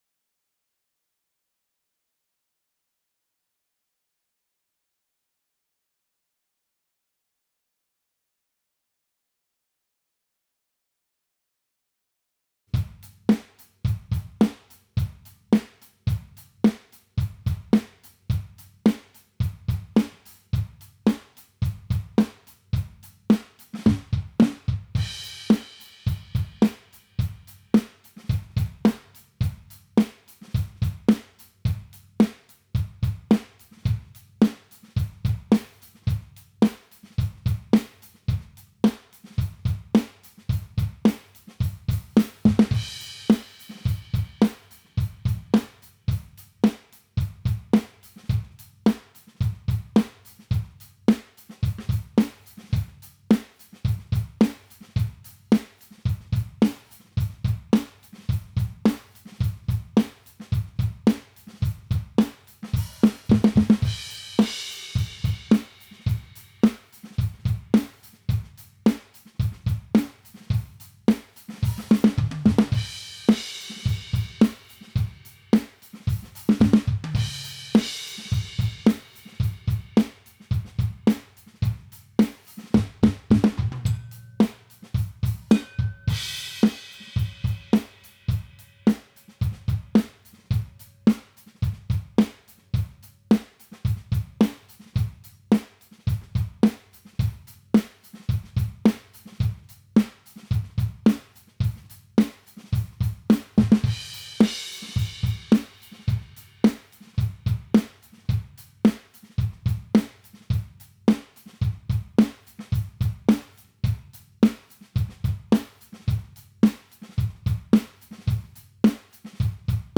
drumsst.wav